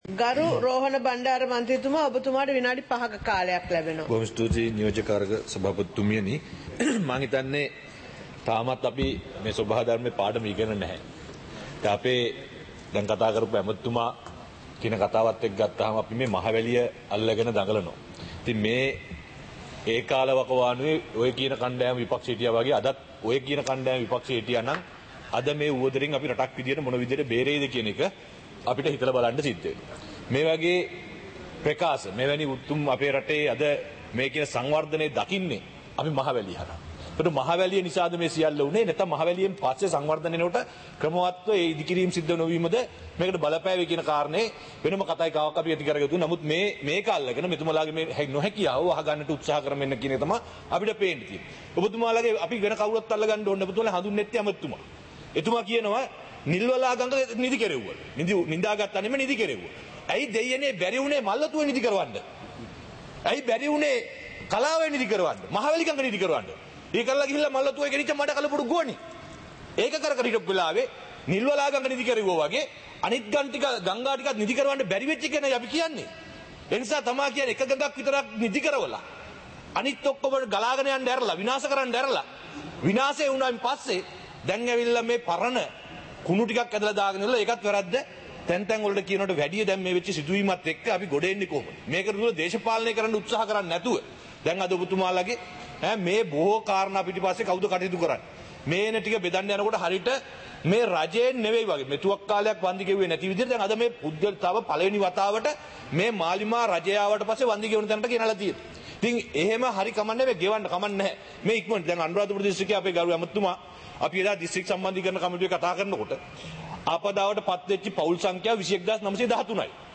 සභාවේ වැඩ කටයුතු (2025-12-19)